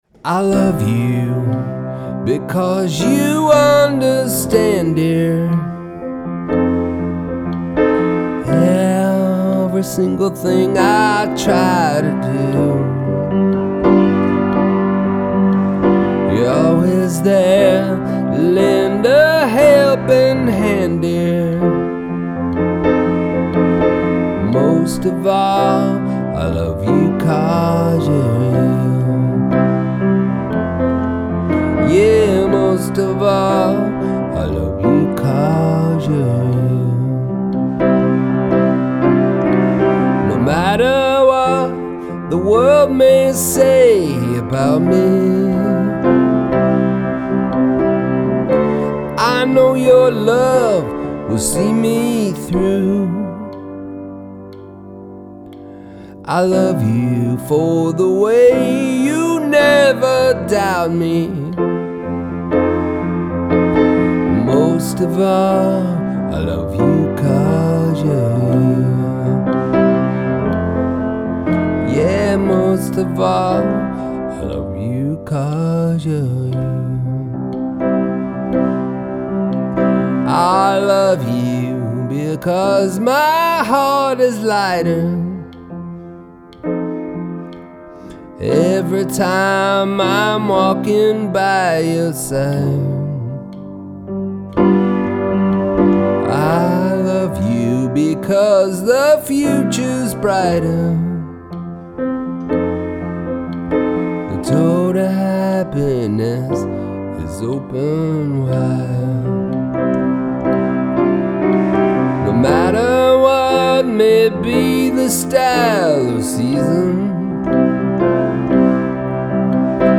Old country song on piano
Hi I havent been recording in a long time but tonight I recorded this old country song on piano and vocal.